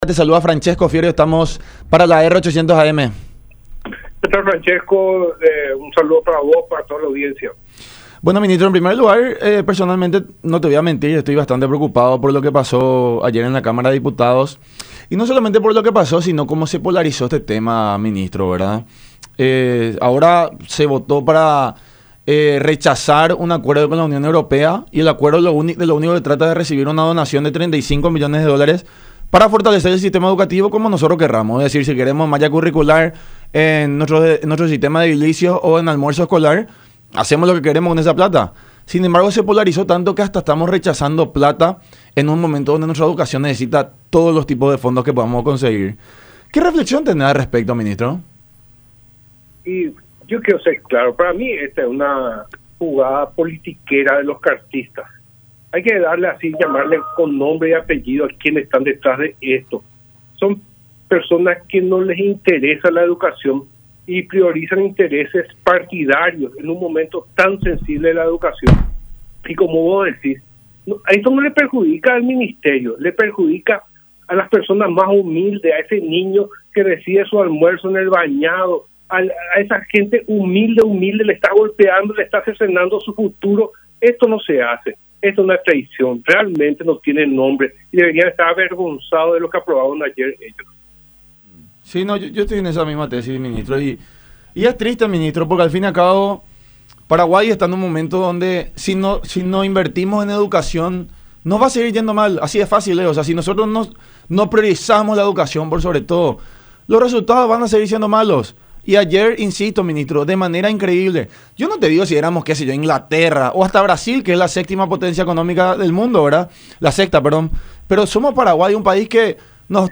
“Esta es una jugada politiquera de los cartistas. Hay que hablar así y decir con nombres y apellidos quiénes están detrás de esto. Son personas a las que no les interesa la educación y priorizan intereses partidarios en un momento tan sensible. Esto no le perjudica al MEC. Les perjudica a las personas más humildes, a los niños que reciben sus almuerzos allá en los bañados. Se les está cercenando su futuro. Esto no hace. Es una traición. No tiene nombre. Ayer yo estaba avergonzado de lo que aprobaron. Parece una pesadilla. Me duele el corazón por lo que están haciendo con los niños. Le están robando a los chicos más humildes”, manifestó Zárate en charla con La Unión Hace La Fuerza por Unión TV y radio La Unión.